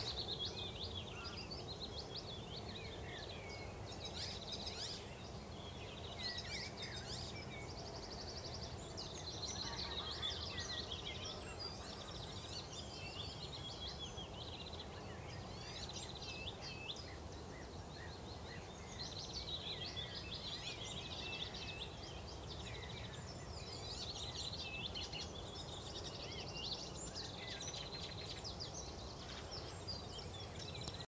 Song difficult to recognize on the recording, because other birds mix up with the icterine warbler´s imitations.
Nombre en inglés: Icterine Warbler
Localización detallada: Ackerdijkse Plassen